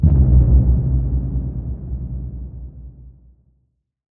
Low End 22.wav